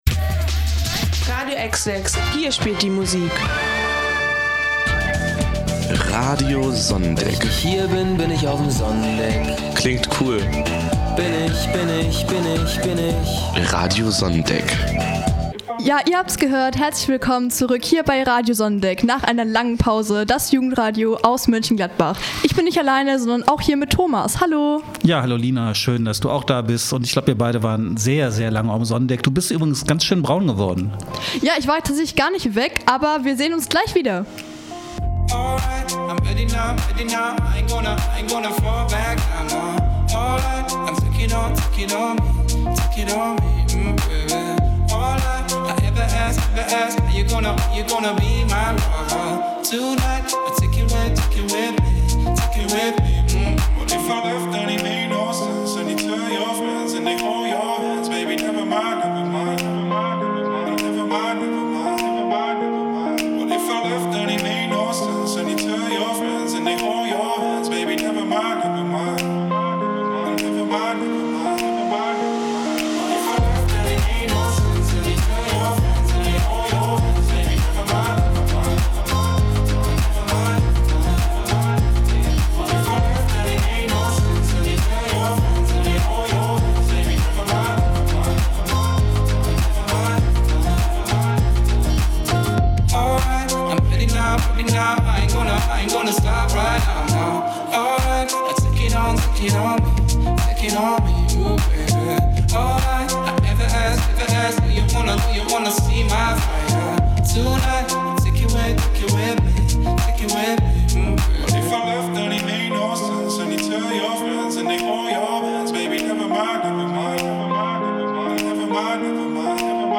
Radio SONNENDECK ist unsere Radioredaktion für Jugendliche und junge Erwachsene von 15 bis 21 Jahren.
Die Redaktionsmitglieder haben überwiegend bereits Erfahrungen aus ihrer Zeit beim Jugendradio Radio JUKI und senden nahezu eigenständig ein eigenes Programm zu selbstgewählten Themen.